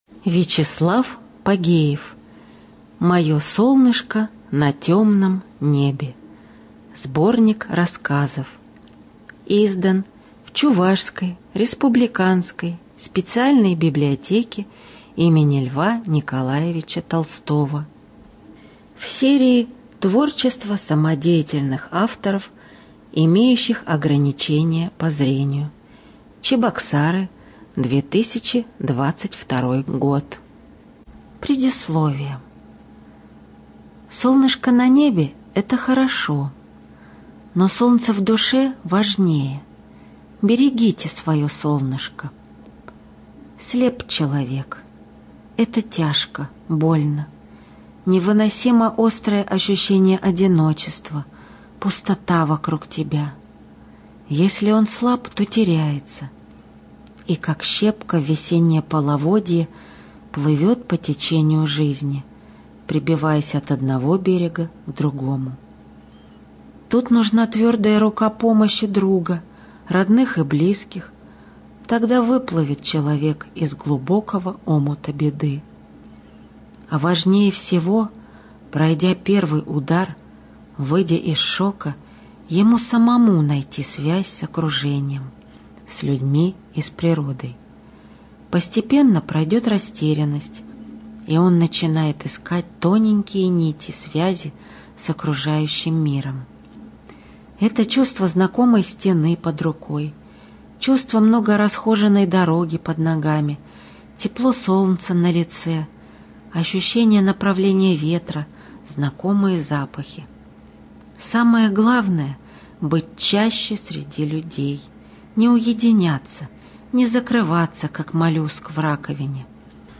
Студия звукозаписиЧувашская республиканская библиотека для слепых имени Льва Николаевича Толстого